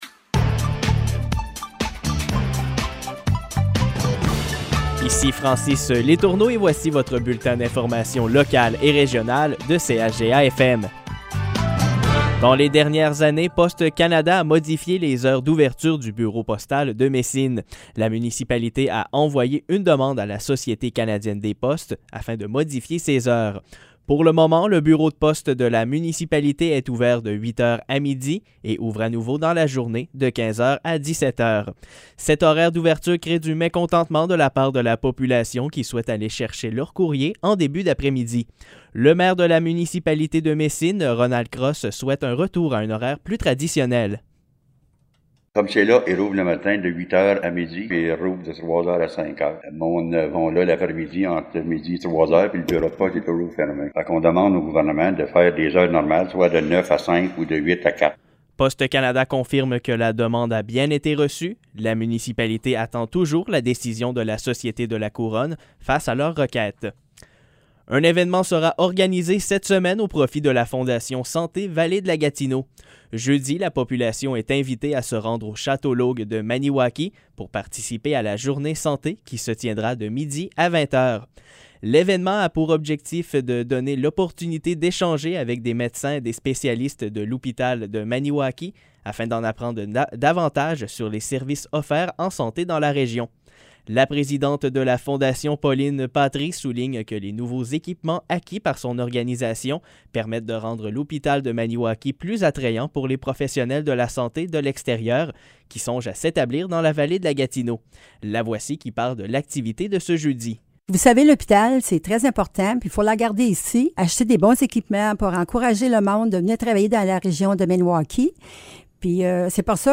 Nouvelles locales - 28 septembre 2021 - 15 h